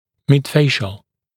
[mɪd’feɪʃl][мид’фэйшл]имеющий отношение к средней трети лица